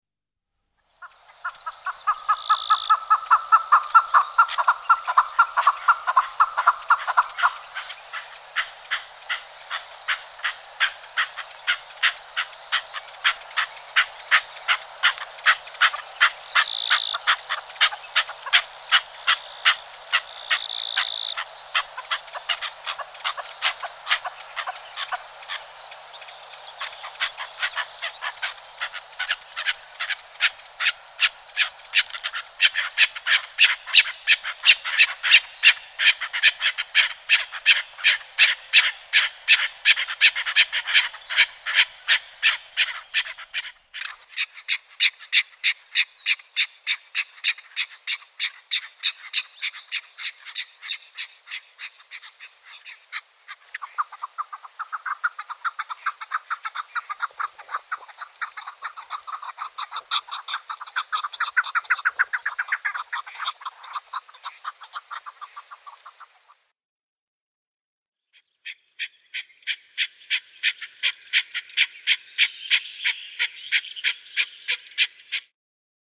Кеклики громко кудахчат